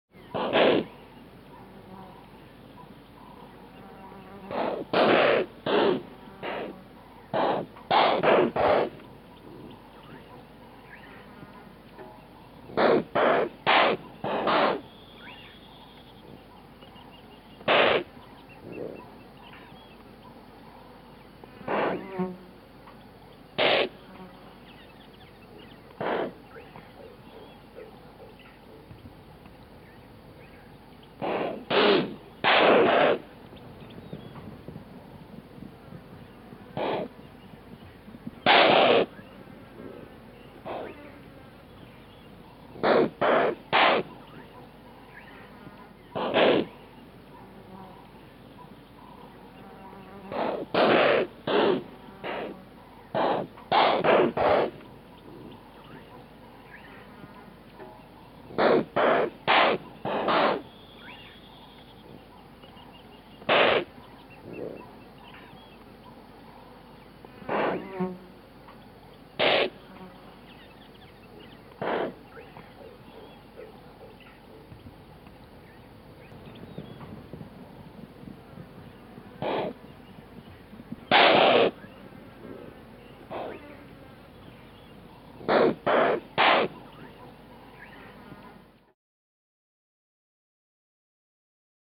دانلود آهنگ آهو از افکت صوتی انسان و موجودات زنده
جلوه های صوتی
دانلود صدای آهو از ساعد نیوز با لینک مستقیم و کیفیت بالا